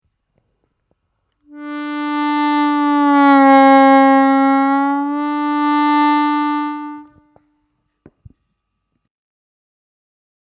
На первом отверстии плавно понижать ноту до самого дна, возвращаясь затем на чистую ноту.
Бенд на первом отверстии